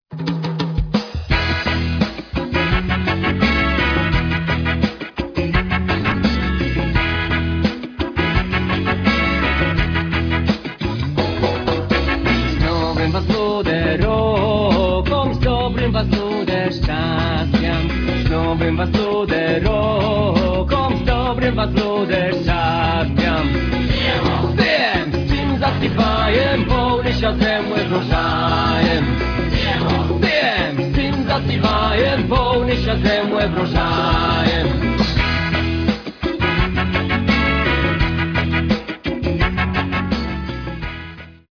Folkrockowe kolędy po polsku i ukraińsku
gitary, bandura
perkusja
organy Hammonda
akordeon
skrzypce
cymbały
puzon
trąbka
saksofony